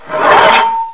hammer_draw.wav